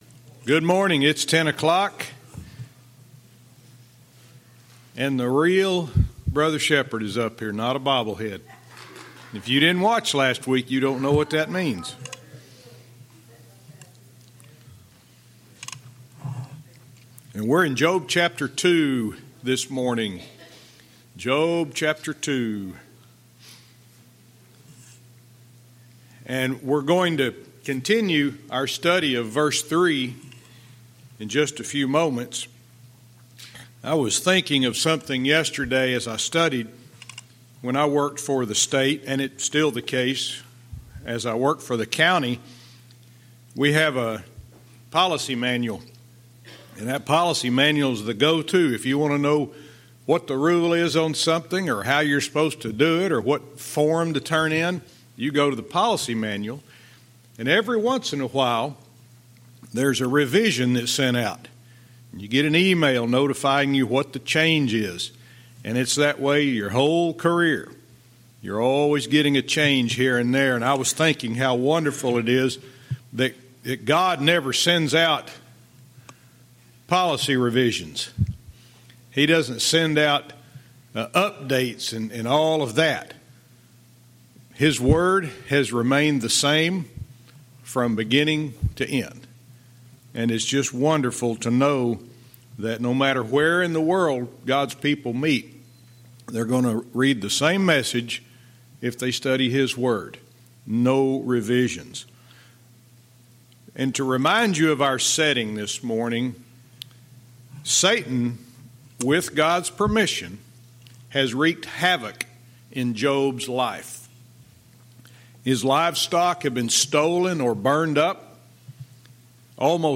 Verse by verse teaching - Job 2:3(cont)-5